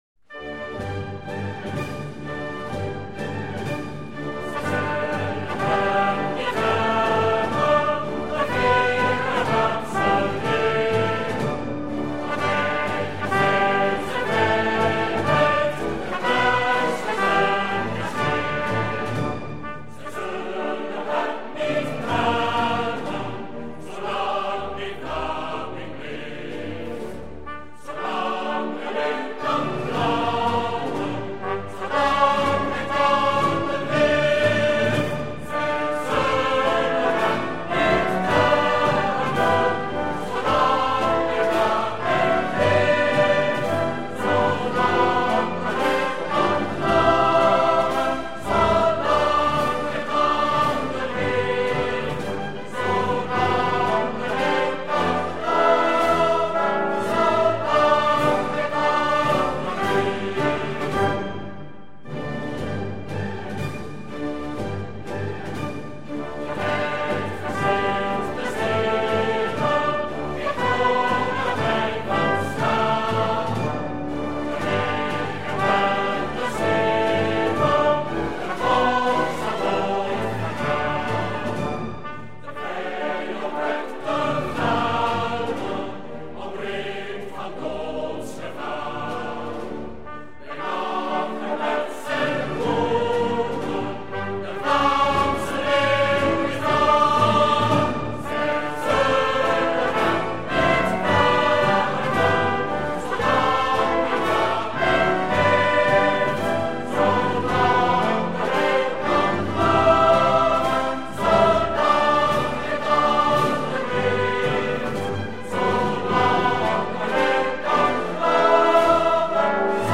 Hieronder dan eindelijk de foto's en de muziek (in bijlage) die gespeeld werd.